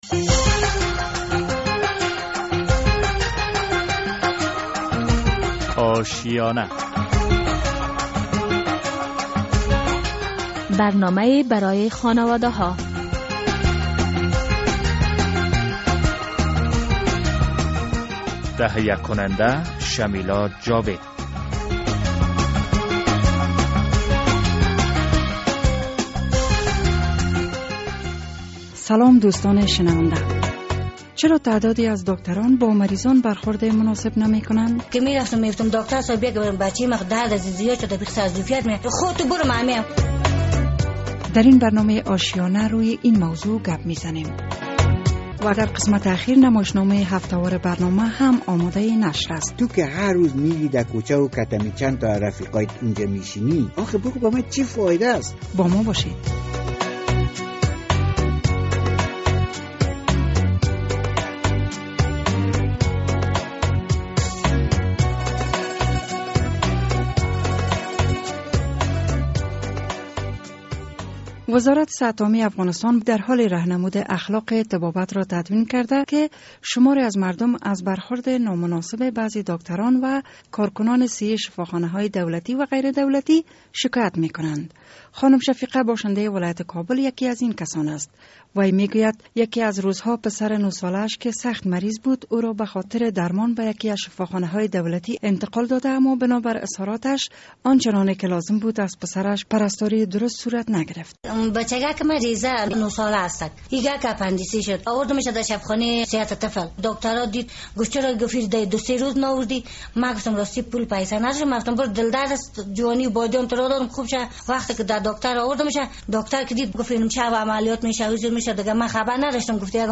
در این برنامهء آشیانه روی این موضوع بحث میشود. و طبق معمول نمایشنامه هم در اخیر این برنامه در نظر گرفته شده است.